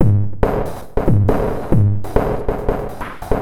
E Kit 31.wav